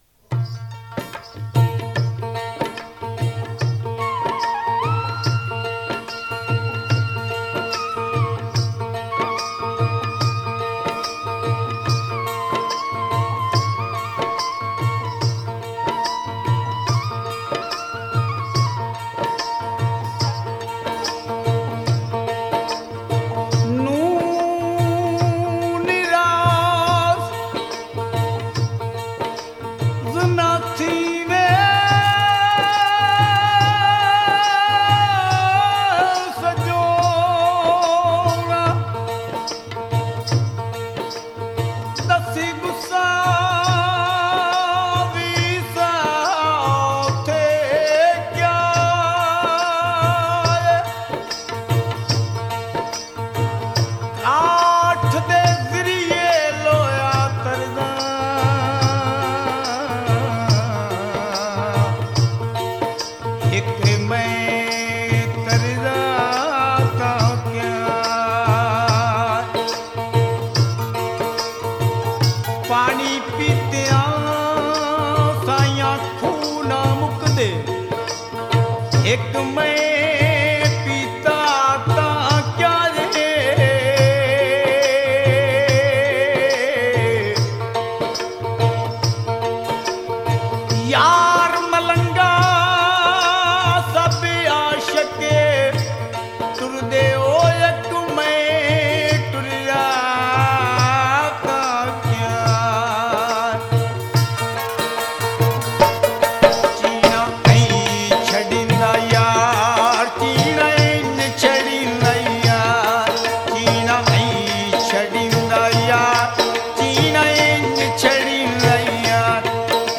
Punjabi Love song